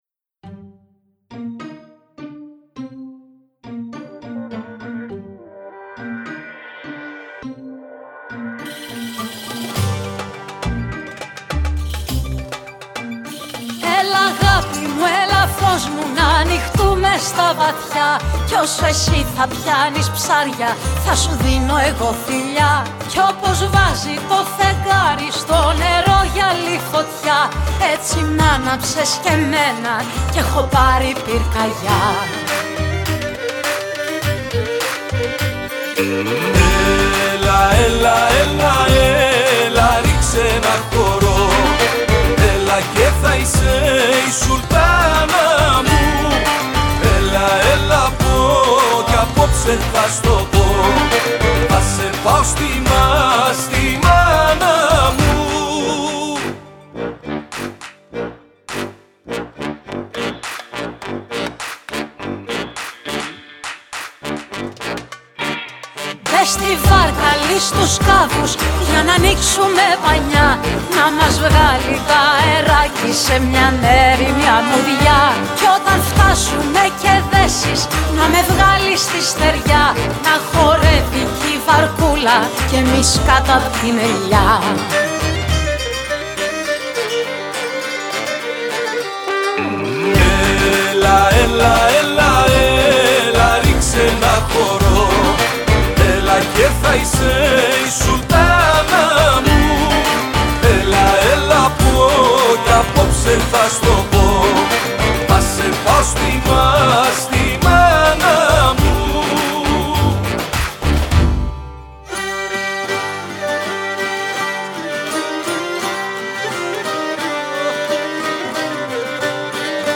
Το ντουέτο που εξυμνεί τον έρωτα με τον καλύτερο τρόπο